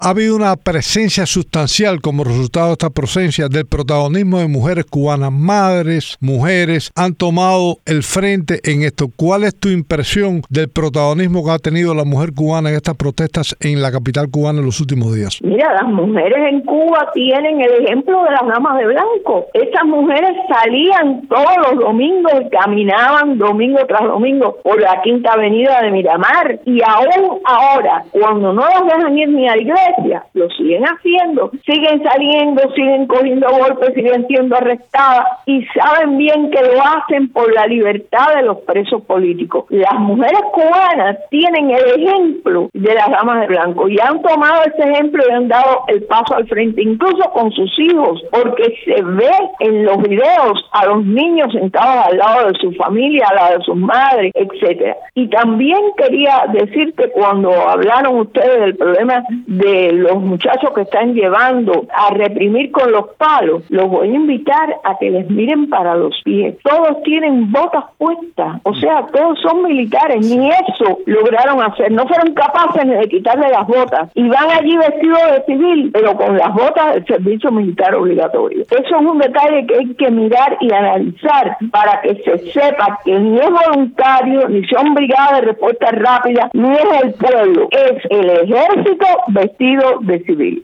Economista cubana habla sobre aumento de presencia femenina en las protestas